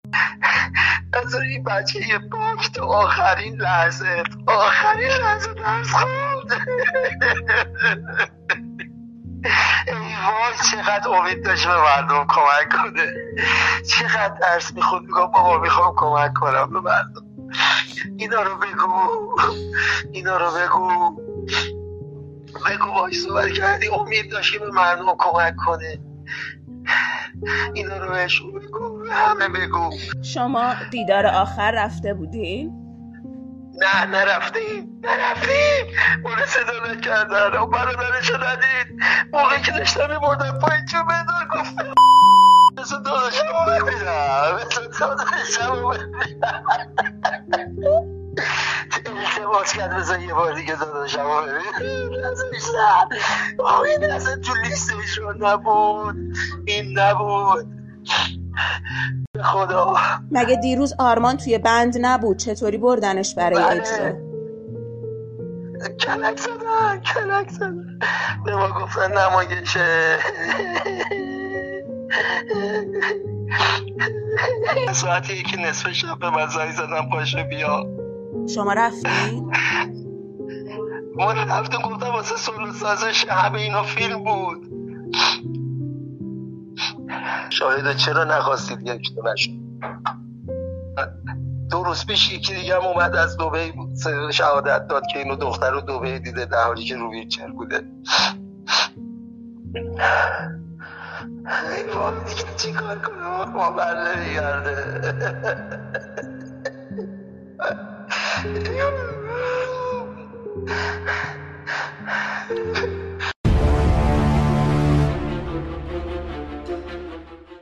صوت| گریه‌های تکان‌دهنده